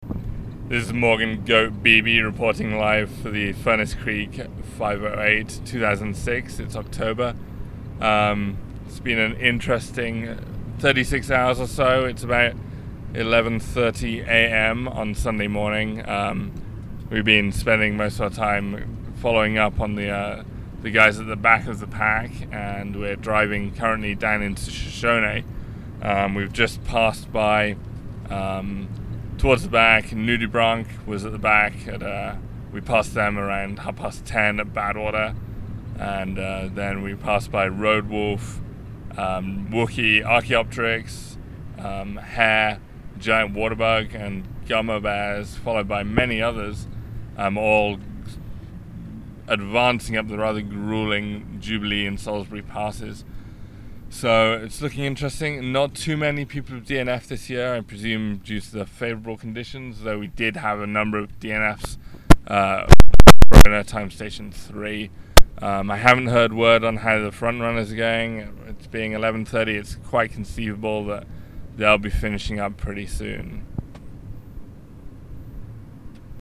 Update while approaching Shoshone at the back of the pack
shoshone_update_1130am.mp3